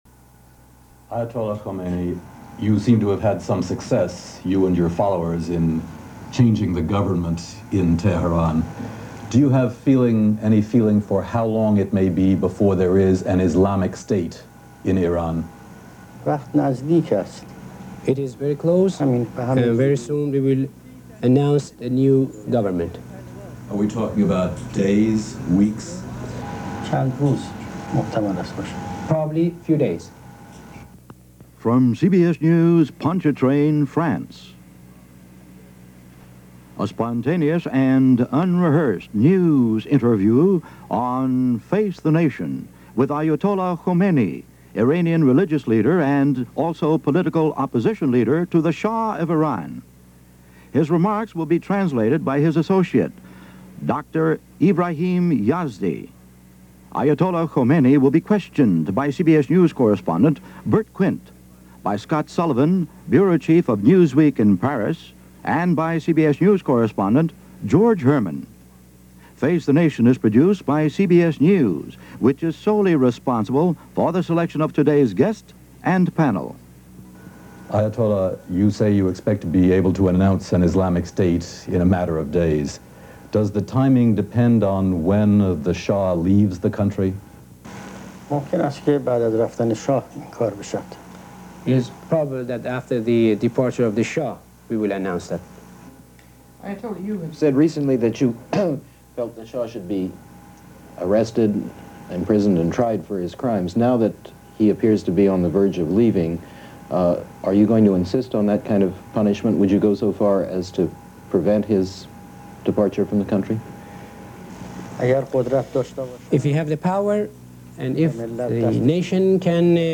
A Word Or Two From Ayatollah Khomeini - January 14, 1979 - Past Daily After Hours Reference Room - CBS Face The Nation.